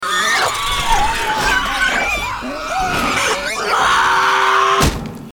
Female Darkseeker howls
Tags: I Am Legend I Am Legend movie I Am Legend clips Will Smith movie sounds